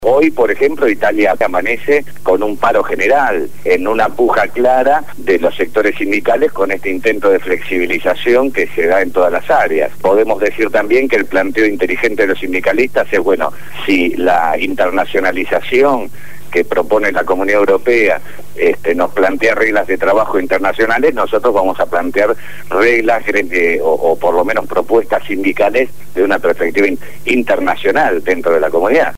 habló desde Italia y dio un completo informe de la situación crítica que vive el país europeo.